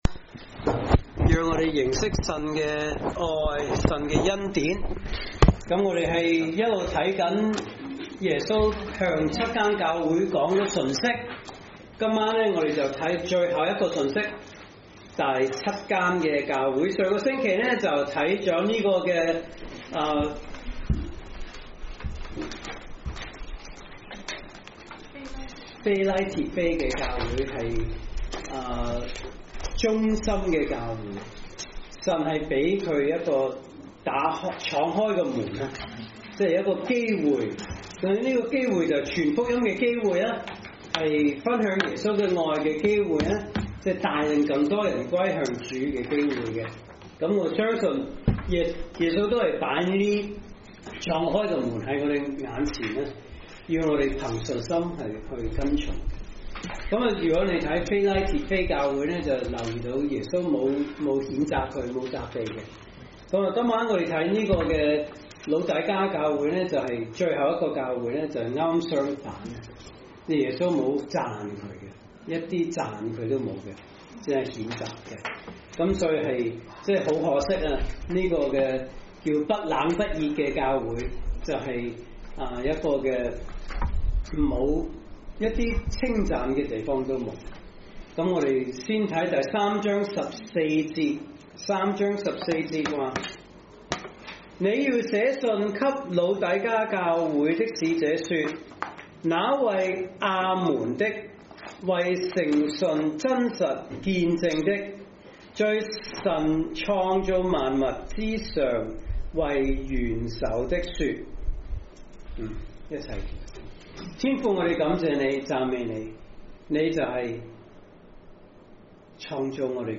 來自講道系列 "查經班：啟示錄"